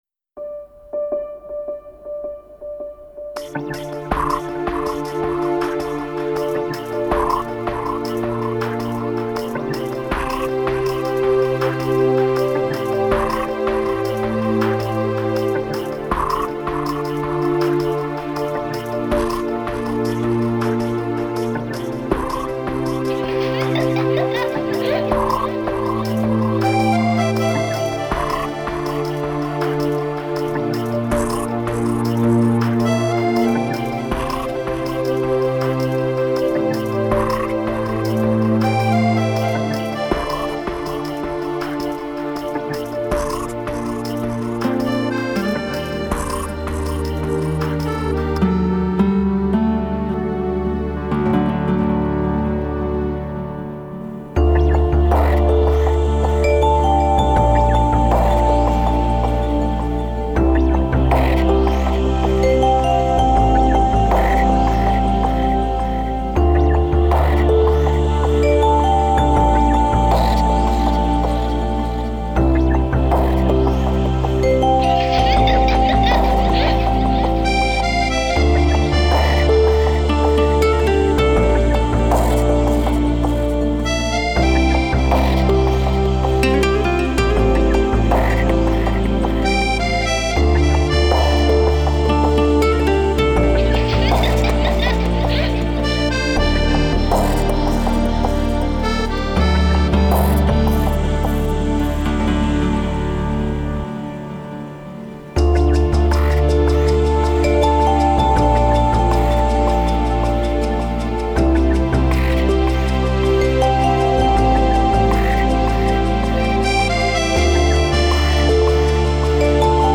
Стиль: Chillout/Lounge